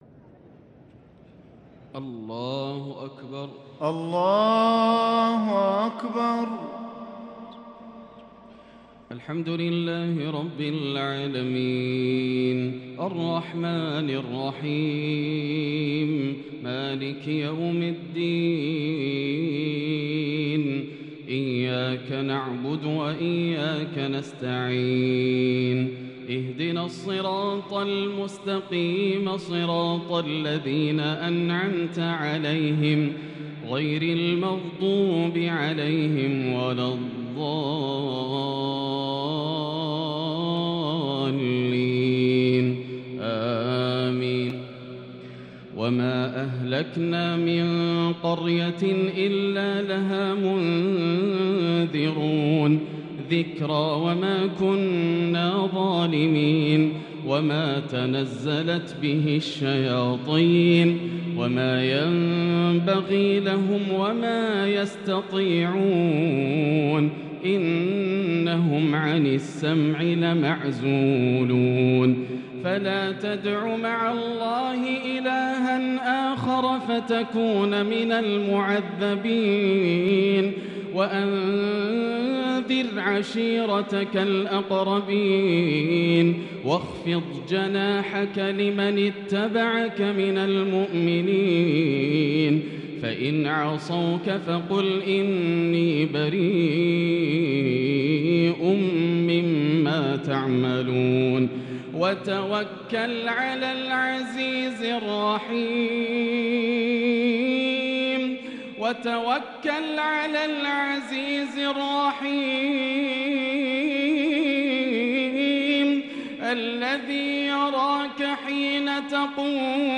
صلاة التراويح l ليلة 23 رمضان 1443هـ l من سورة الشعراء {208 }النمل {1-58} taraweeh prayer The 23rd night of Ramadan1443H | from surah Ash-Shuara and An-Naml > تراويح الحرم المكي عام 1443 🕋 > التراويح - تلاوات الحرمين